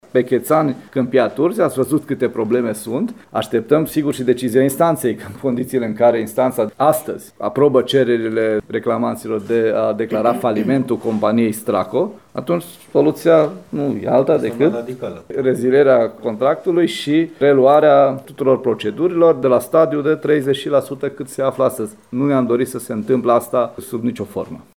Ministrul Lucian Bode: